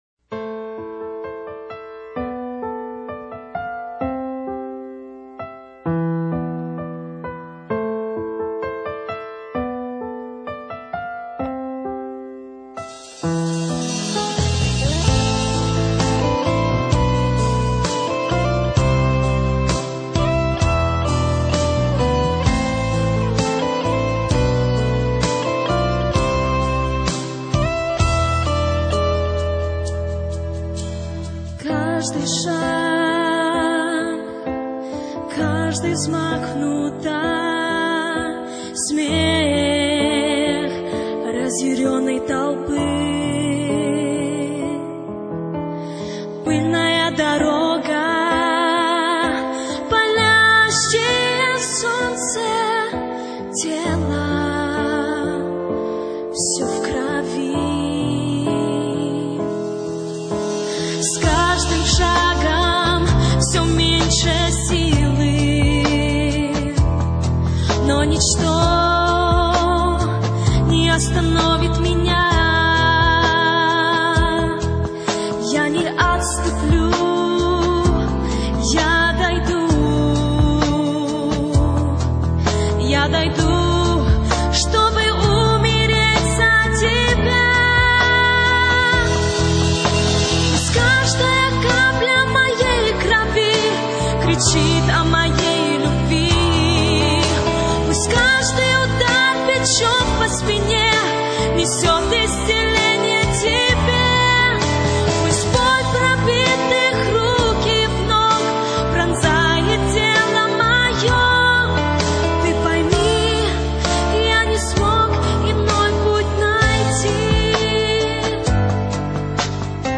1802 просмотра 323 прослушивания 81 скачиваний BPM: 129